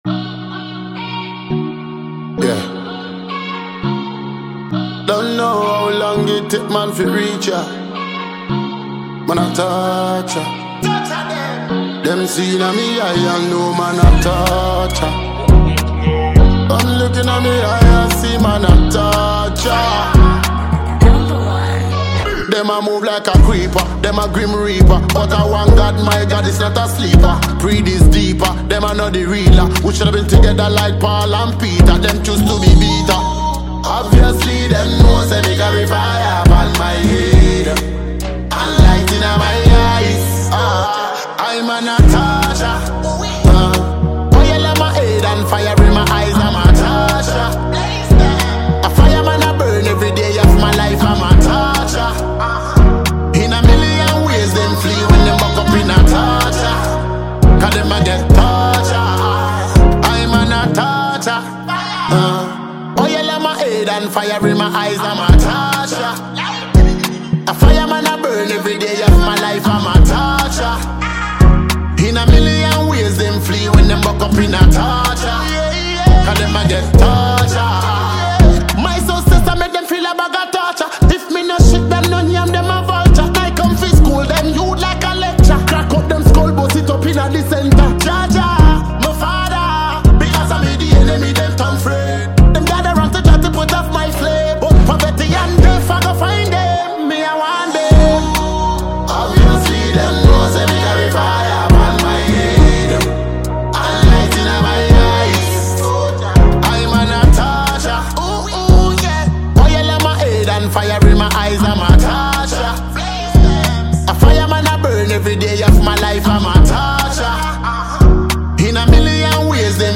Award-winning Ghanaian Afro-dancehall musician